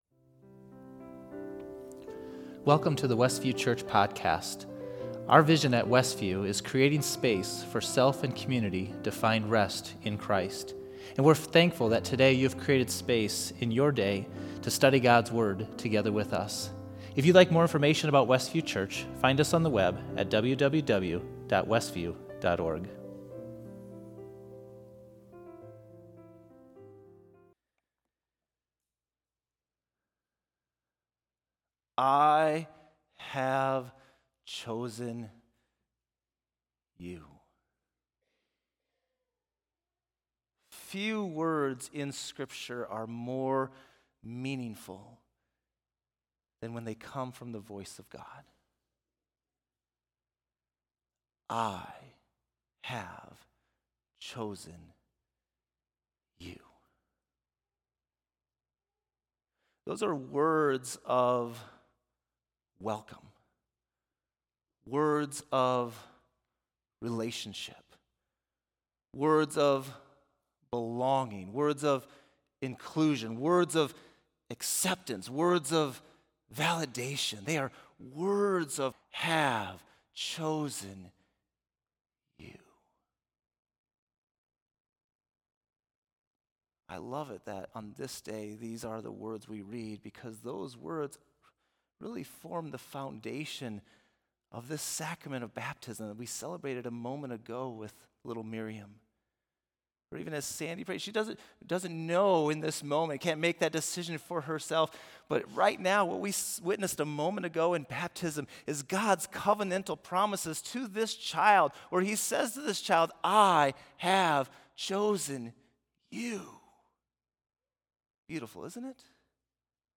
Facebook Livestream